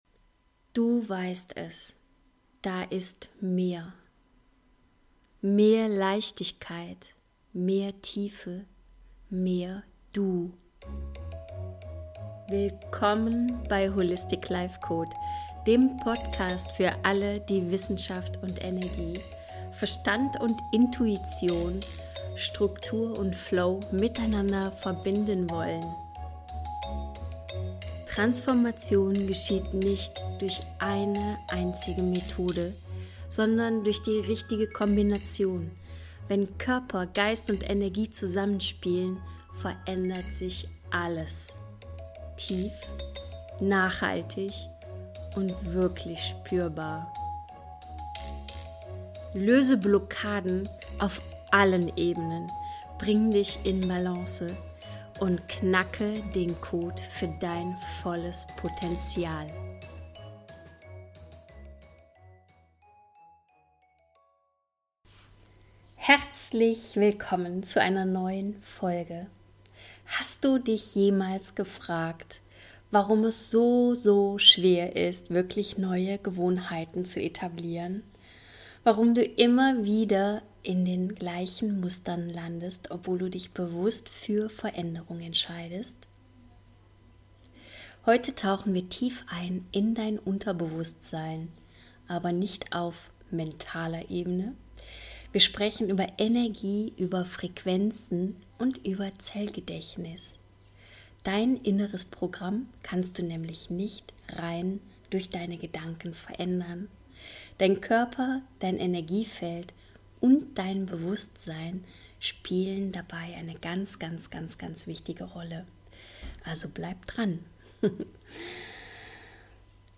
In dieser Folge erfährst du, warum du trotz aller guten Vorsätze immer wieder in alten Mustern landest – und wie du das ändern kannst. Wir sprechen über dein Unterbewusstsein als energetische „Software“, die Rolle deines Nervensystems, das Zellgedächtnis und wie du durch Frequenzarbeit und Zellnahrung neue Wege beschreitest. Mit einer geführten Meditation helfen wir dir, alte Energien loszulassen, dein Feld zu reinigen und deine Zellen mit Licht zu aktivieren.